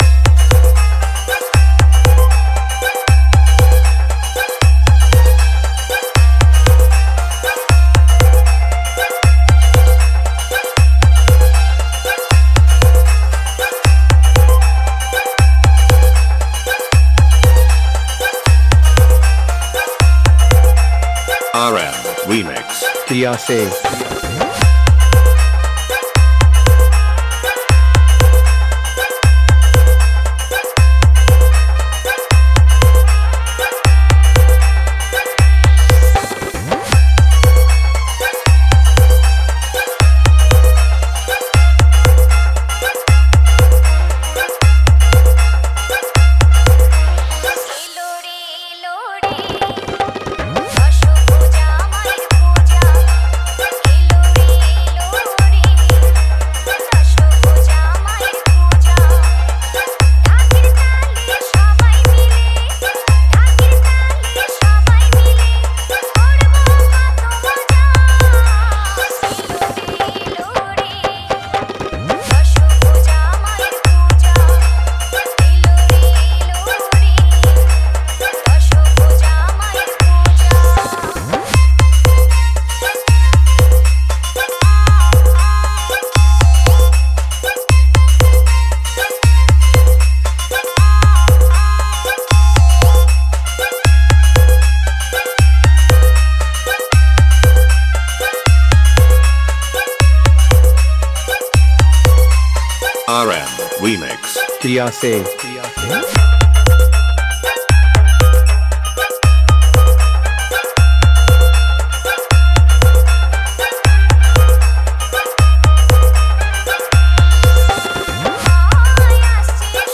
দুর্গ উৎসব স্পেশাল বাংলা নতুন স্টাইল ভক্তি হামবিং মিক্স 2024